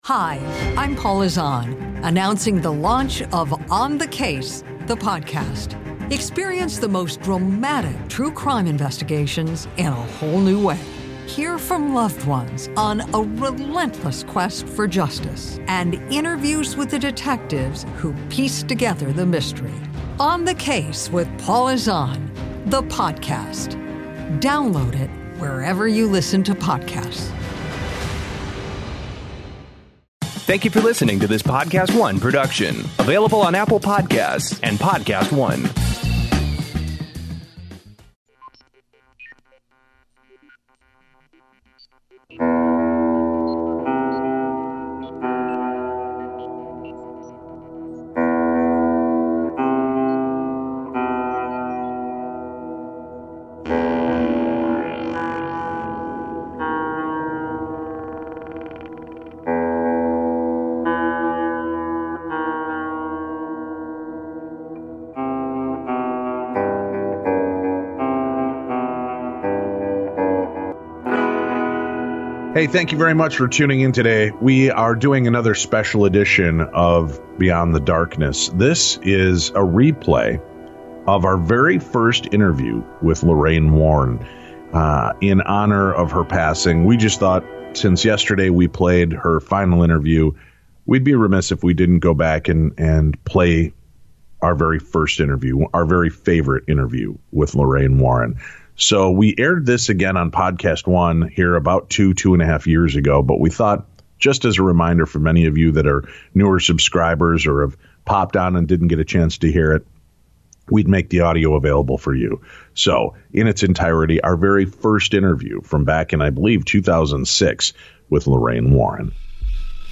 In memory of Lorraine's passing last Week, BEYOND the DARKNESS is airing a special encore presentation of the very first episode that she appeared with us.